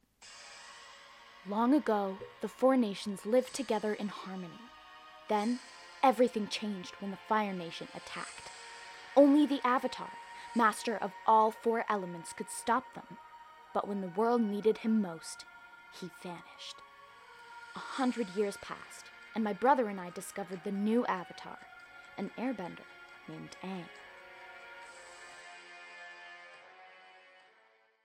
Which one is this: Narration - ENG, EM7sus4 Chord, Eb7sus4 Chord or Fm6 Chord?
Narration - ENG